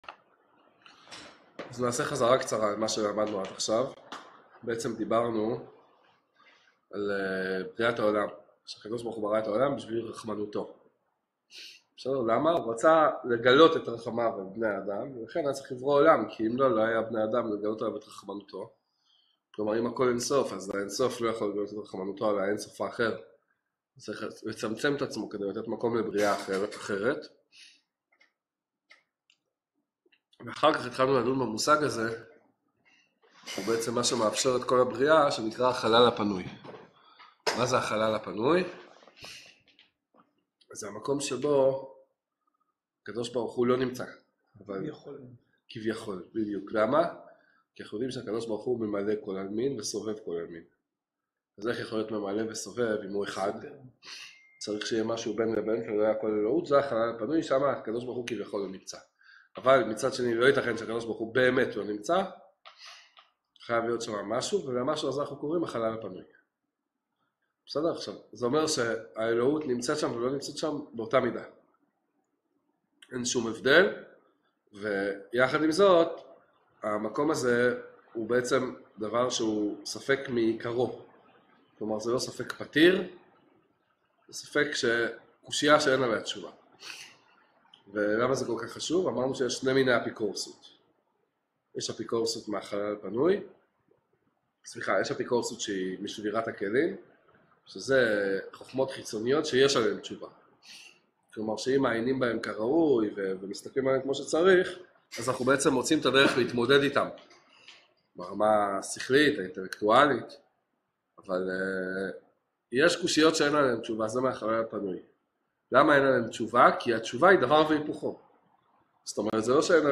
ממשיך בלימוד התורה הקבלית העמוקה ביותר רבי נחמן, שיעור לפני אחרון!